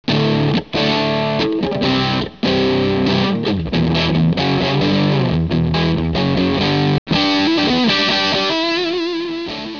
FUZZ-BOXES